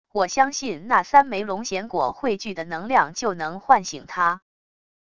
我相信那三枚龙涎果汇聚的能量就能唤醒它wav音频生成系统WAV Audio Player